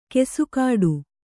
♪ kesukāḍu